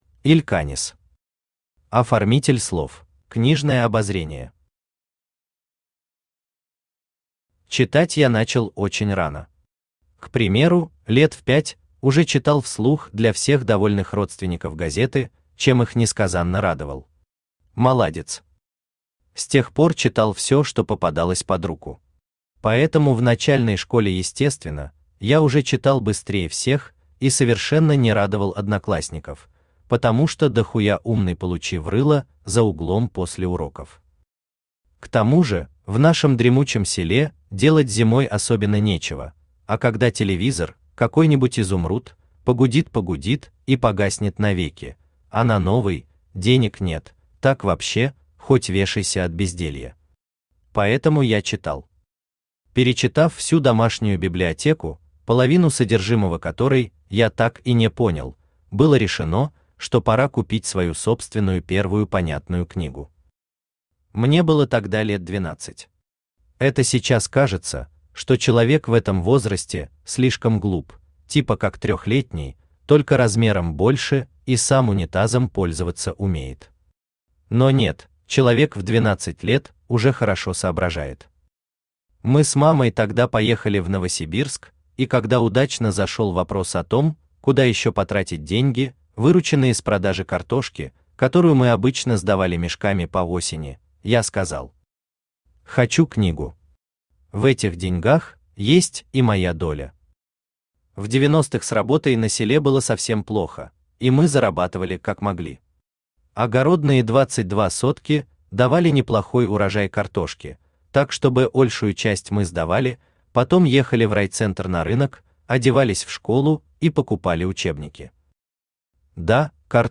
Аудиокнига Оформитель слов | Библиотека аудиокниг
Aудиокнига Оформитель слов Автор Иль Канесс Читает аудиокнигу Авточтец ЛитРес.